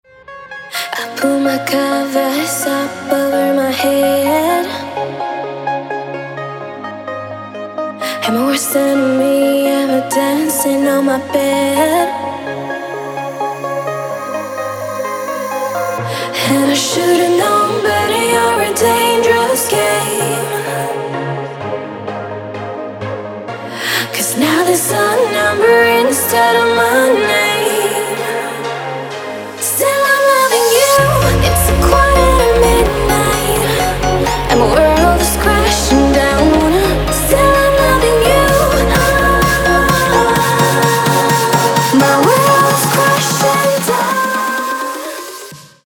• Качество: 192, Stereo
женский вокал
dance
club
vocal